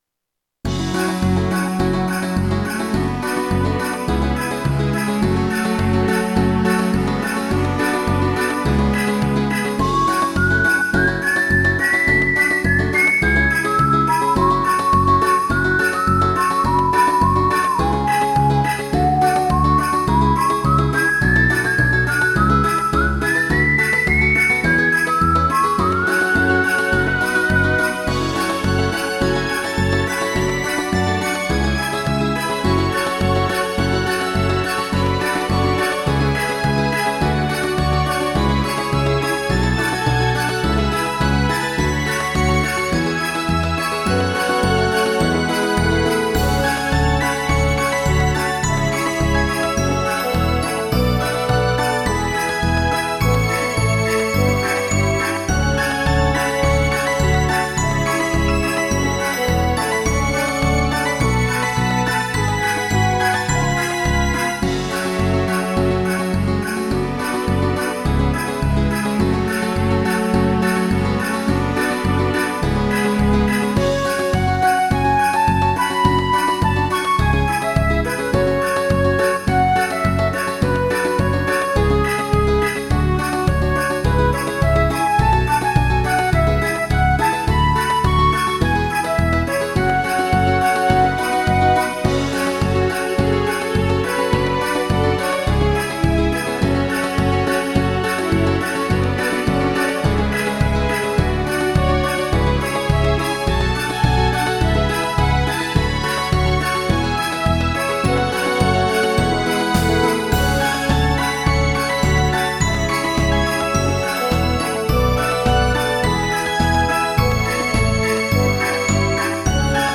趣味でゲームBGMの適当な簡易アレンジを作って遊んでます。
前半のフィールド曲で、サントラとゲームの間くらいのアレンジ。キーは原曲よりも高め。